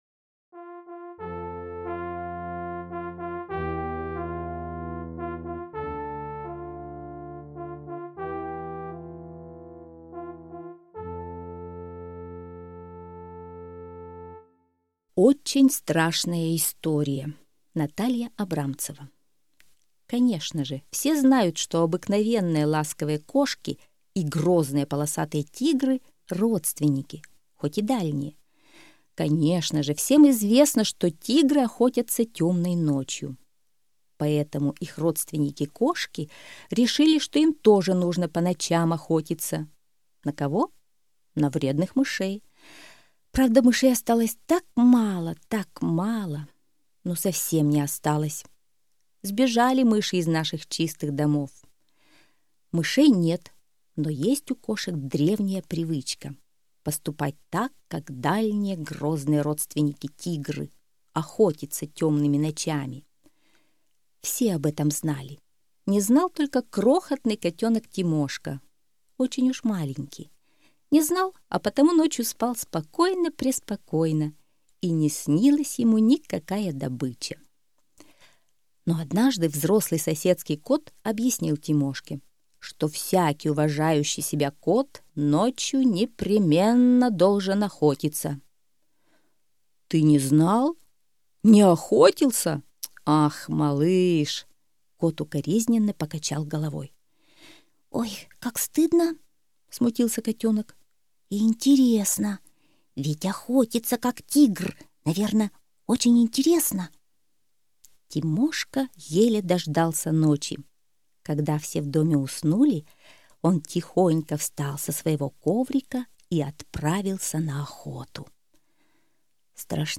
Слушайте Очень страшная история - аудиосказка Абрамцевой Н. Сказка про маленького котеночка Тимошку, который не знал, что ночью надо не спать, а охотиться.